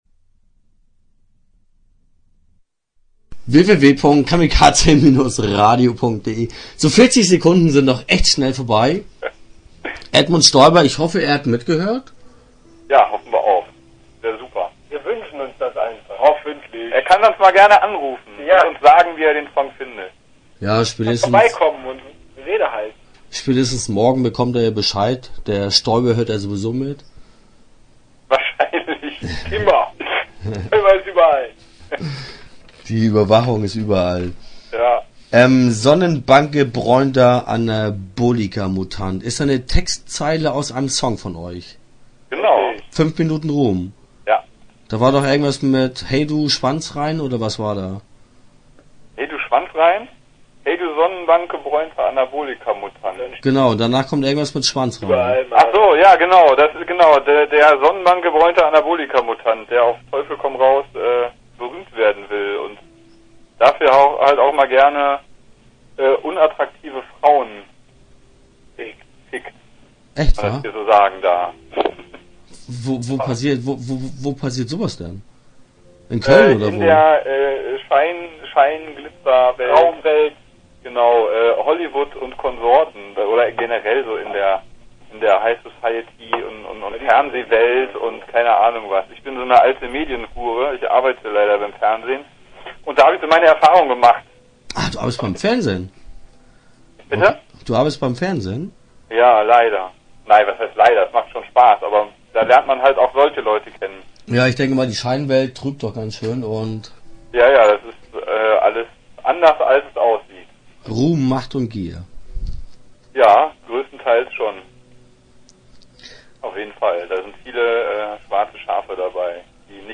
Interview Teil 1 (10:58)